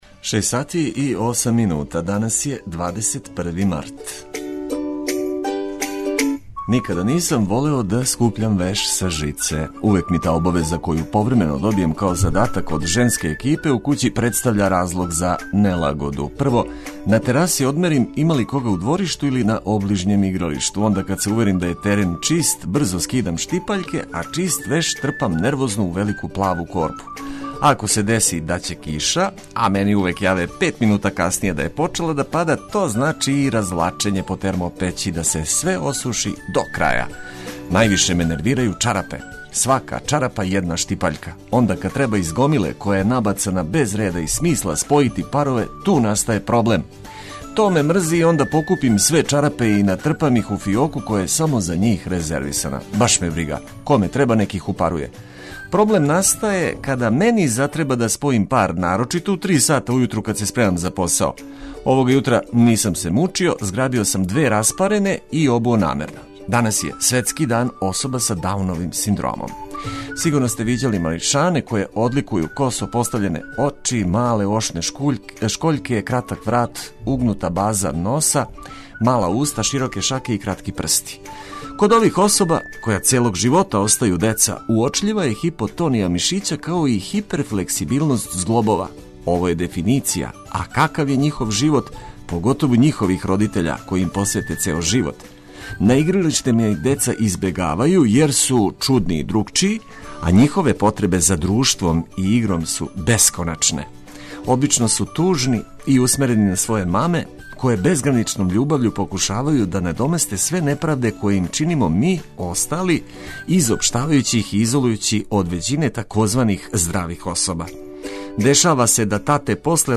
Током јутра најважније информације о приликама у Србији уз веселу музику која ће вам олакшати устајање из кревета.